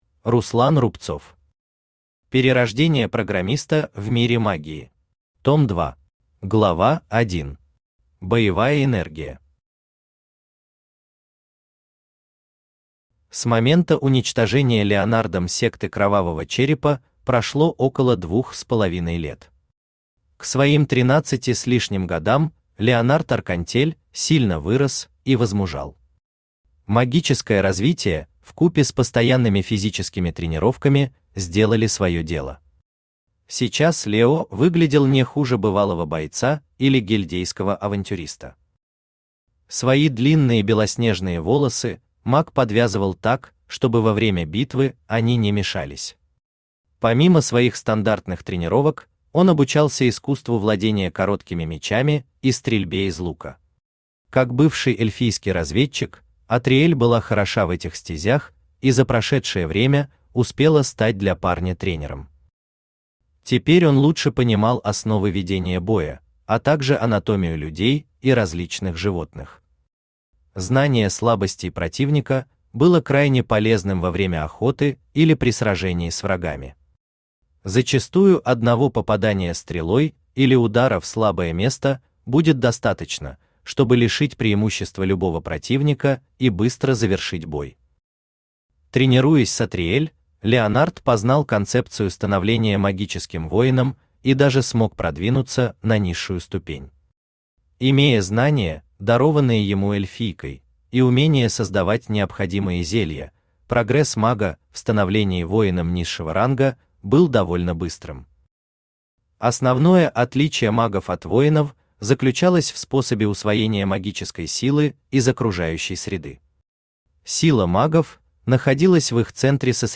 Том 2 Автор Руслан Рубцов Читает аудиокнигу Авточтец ЛитРес.